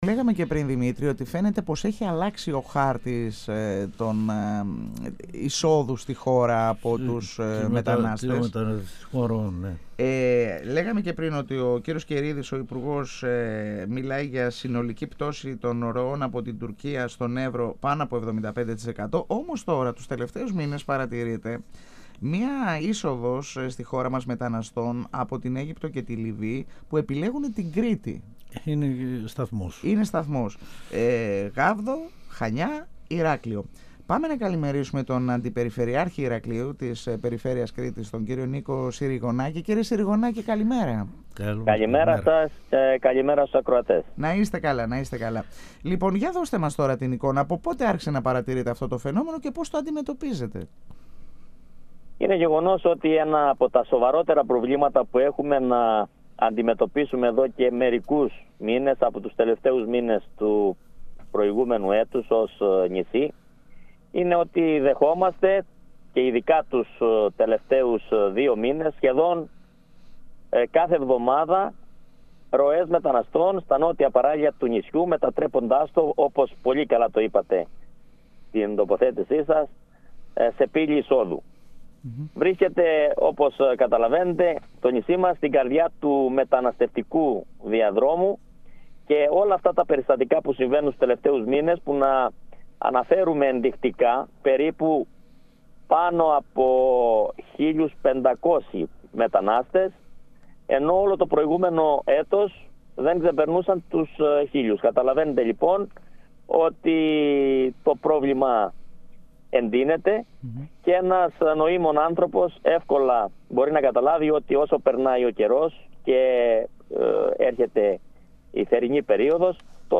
Για την αύξηση των μεταναστευτικών ροών στην Κρήτη από την Αίγυπτο αναφέρθηκε ο αντιπεριφερειάρχης Ηρακλείου Νίκος Συριγωνάκης μιλώντας στην εκπομπή «Εδώ και Τώρα» του 102FM της ΕΡΤ3.